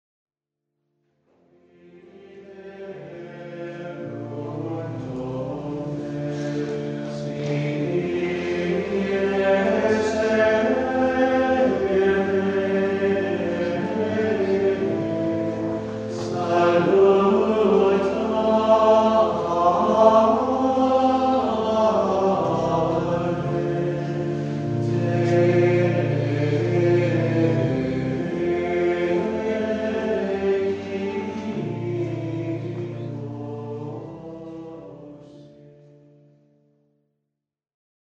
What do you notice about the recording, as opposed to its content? CD-Quality, Windows Media (WMA) Format